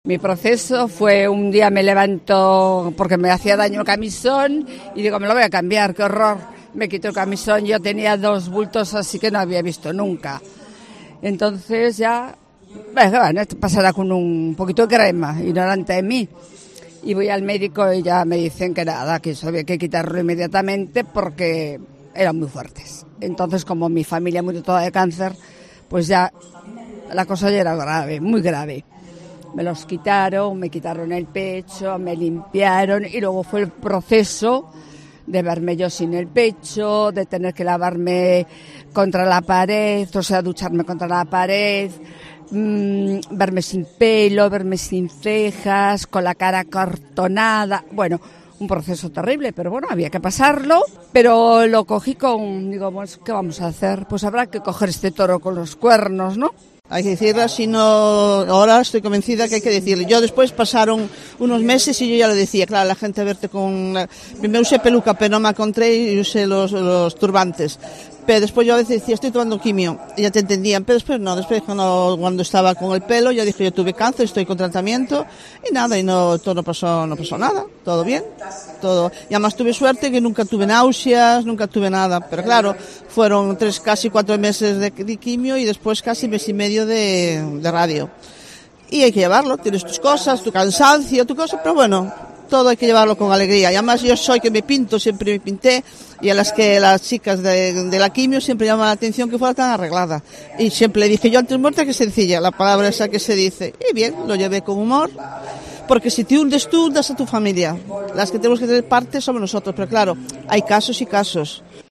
Escucha el testimonio de dos mujeres que han vencido al cáncer en Pontevedra